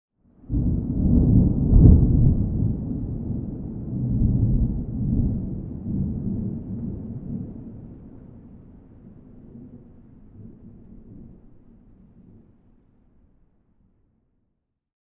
thunderfar_13.ogg